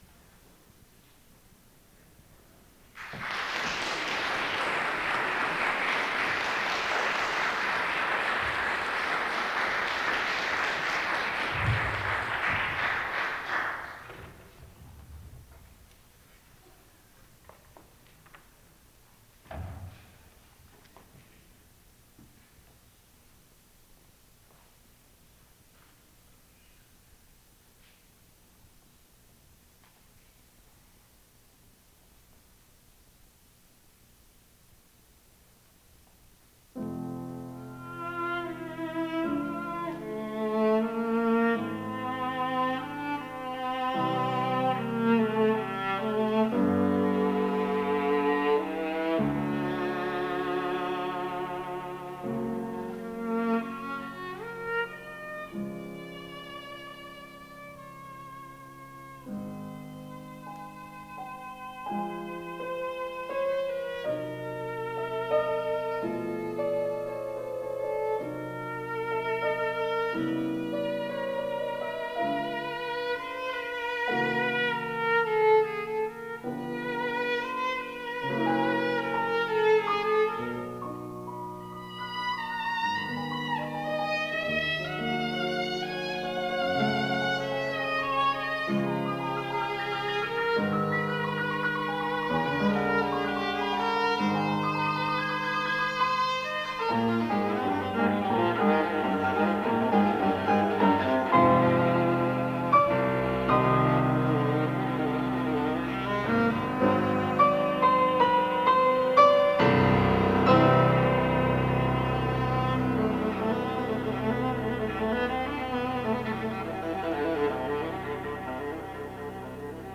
FACULTY AND GUEST ARTIST RECITAL
viola
piano
oboe Monday
2003 8:00 p.m. Lillian H Duncan Recital Hall
for Oboe, Viola, and Piano